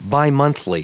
Prononciation du mot bimonthly en anglais (fichier audio)
Prononciation du mot : bimonthly